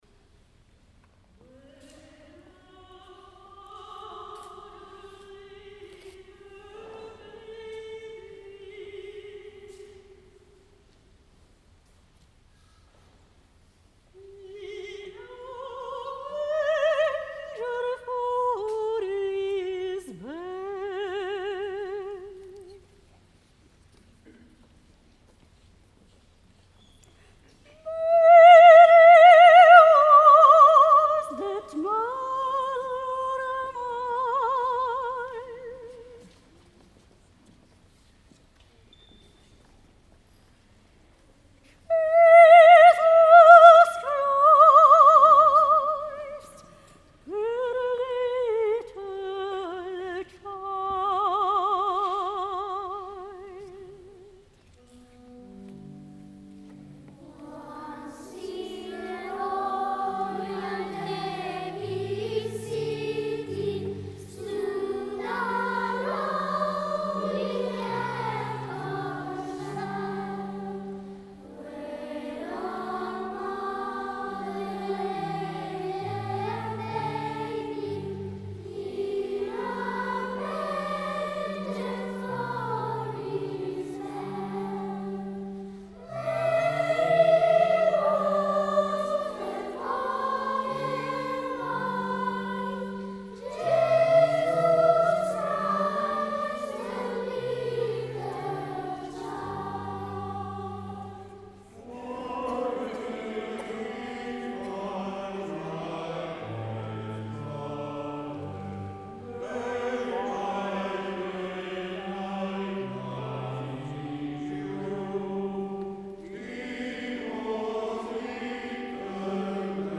S. Gaudenzio church choir Gambolo' (PV) Italy
22 dicembre 2025 - Concerto di Natale
audio del concerto